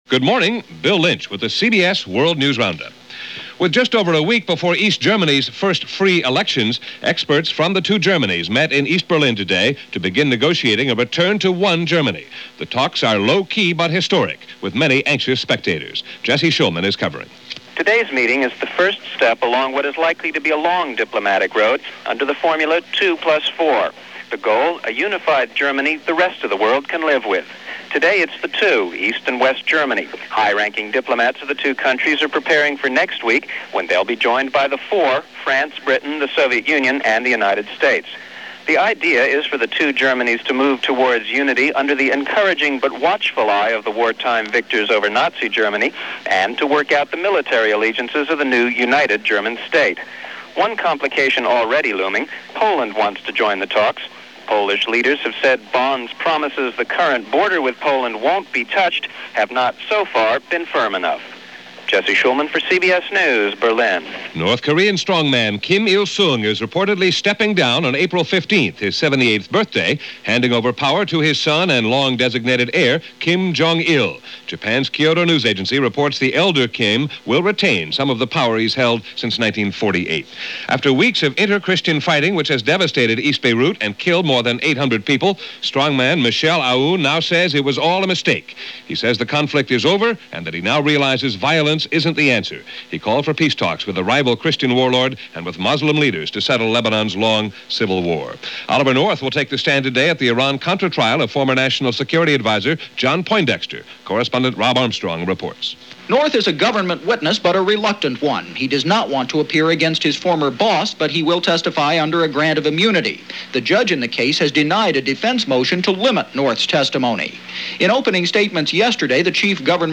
And that’s just a sample of what went on, this March 9, 1990 as presented by The CBS World News Roundup.